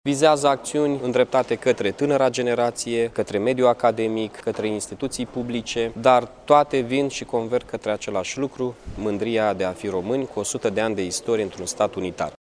Primarul Iaşului, Mihai Chirica, a precizat că, în total, în cadrul programului, vor fi peste 150 de evenimente, ce se vor desfăşura pe parcursul a 3 ani: